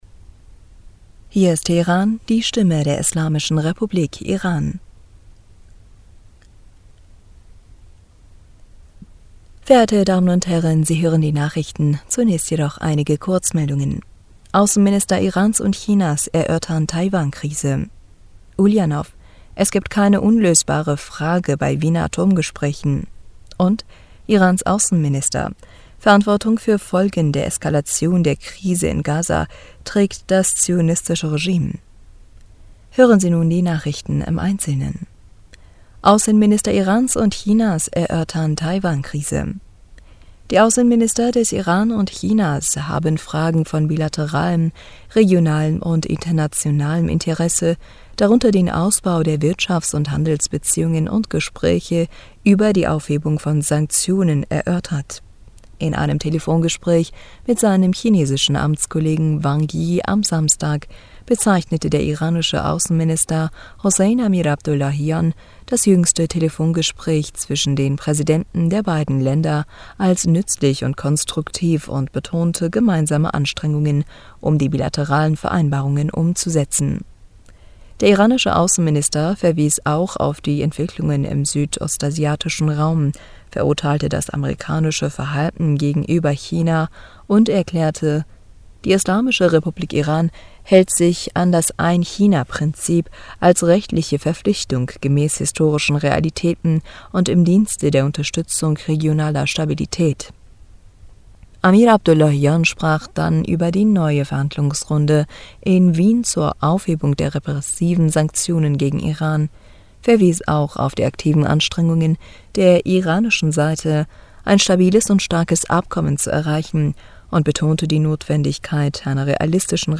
Nachrichten vom 7. August 2022